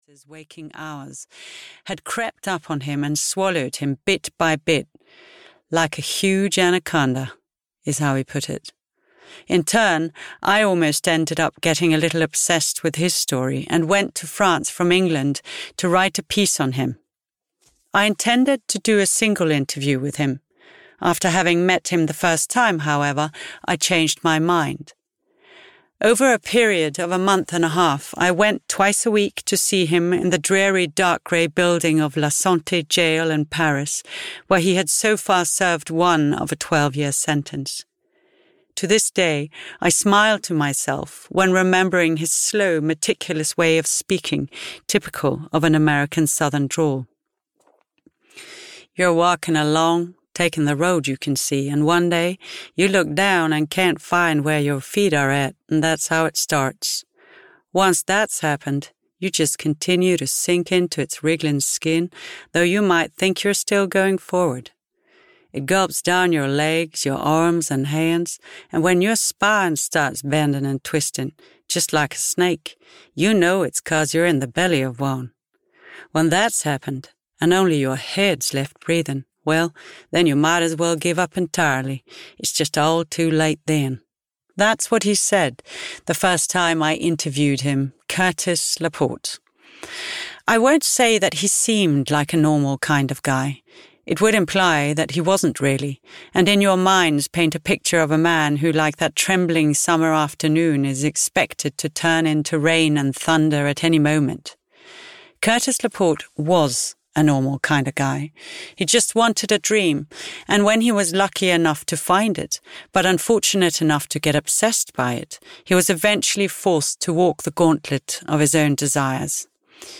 Looking at Harry and Other Stories (EN) audiokniha
Ukázka z knihy
• InterpretNatasha Illum Berg